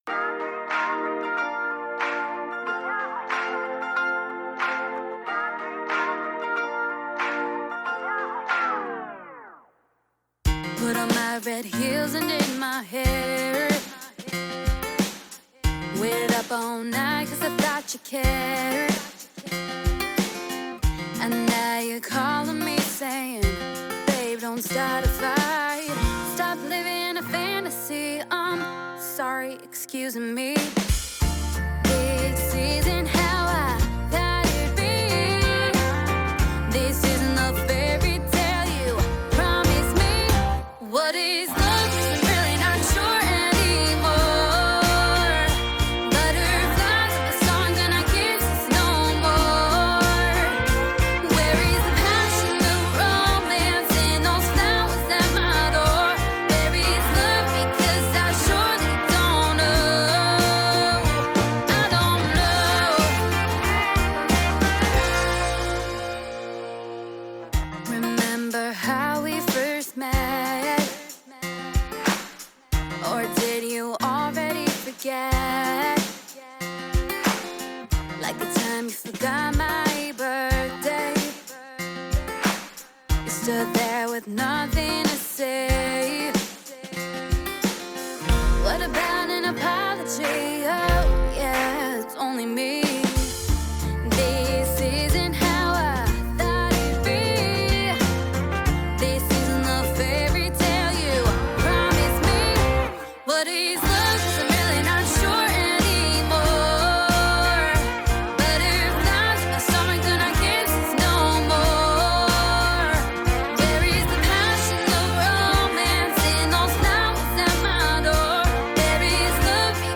Country, pop, female vocals. 1.8 MB.